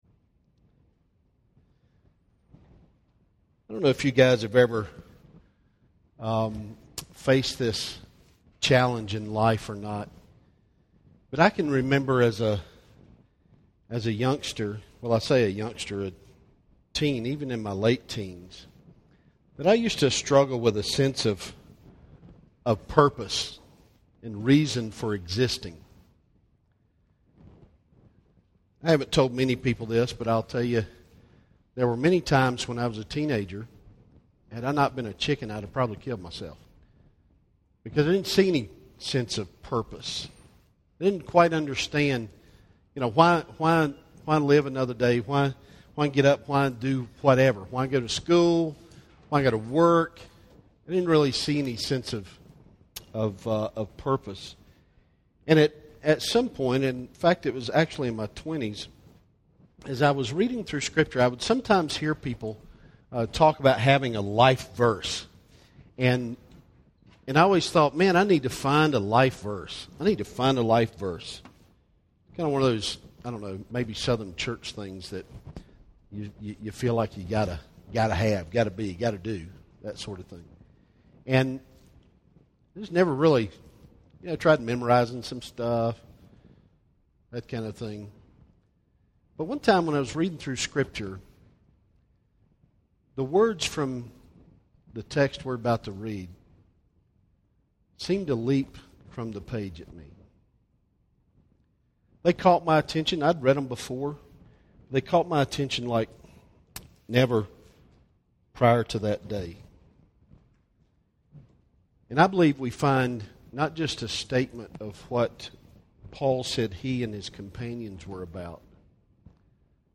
The Way Fellowship Church’s annual men’s retreat this year (2013). Our weekend together focused on the theme of living missionally. Will be obey the command to live intentional lives for the purpose of making disciples?